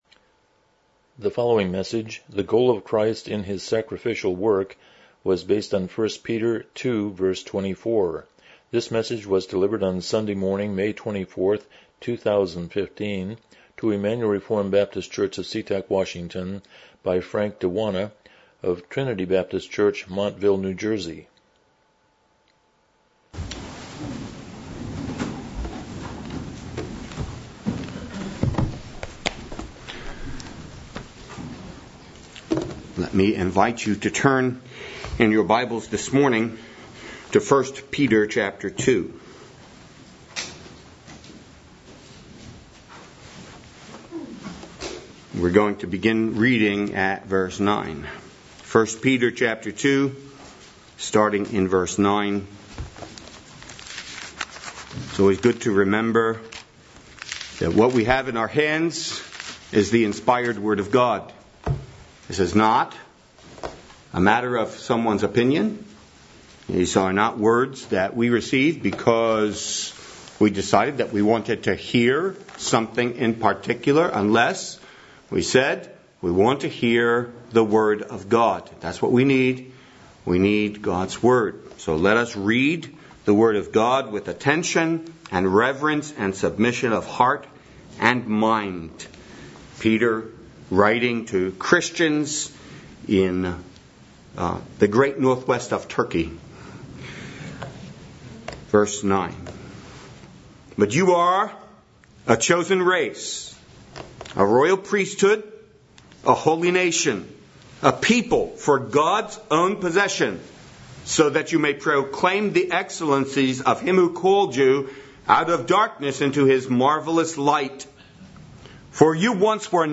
1 Peter 2:24 Service Type: Morning Worship « Fighting the Good Fight